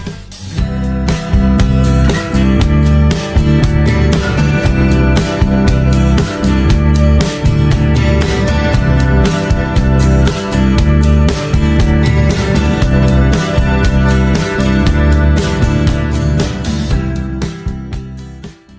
Category: Arabic Ringtones